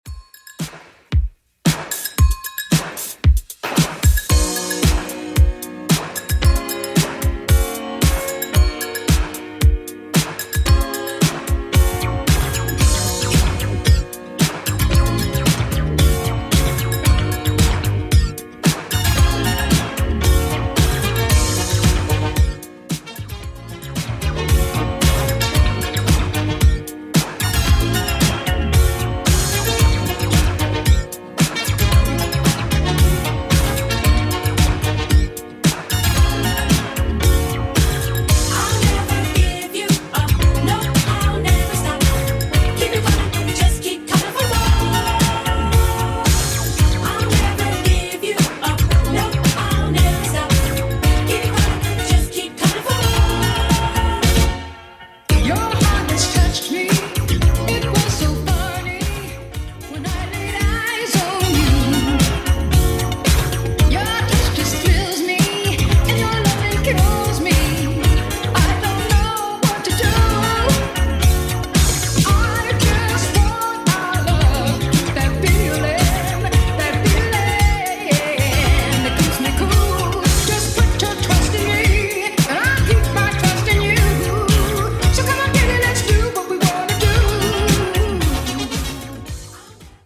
Genre: 2000's
BPM: 103